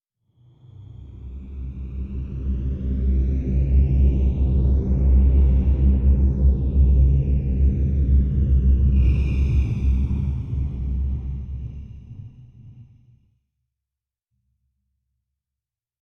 ambient_drone_2.ogg